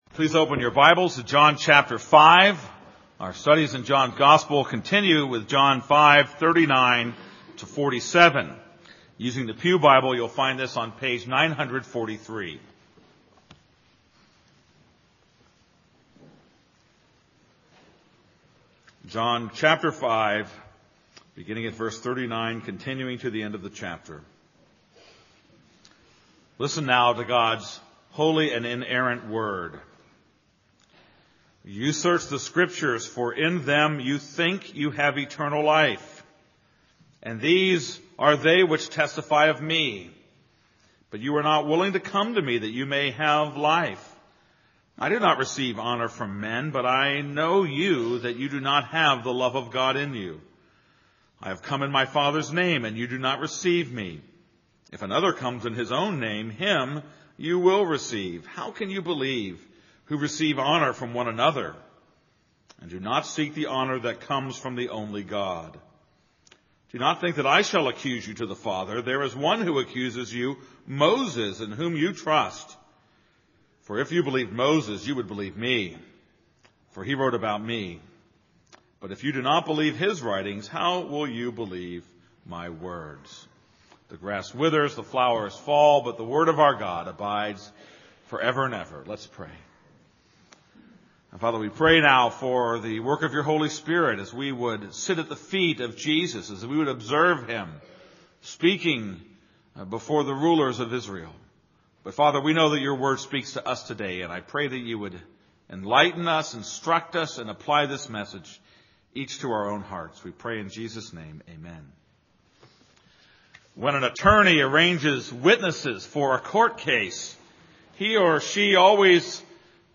This is a sermon on John 5:39-47.